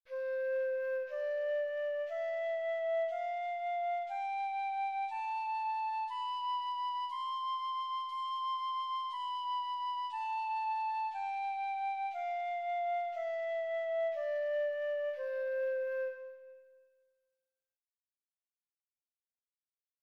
Ottavino
Ottavino.mp3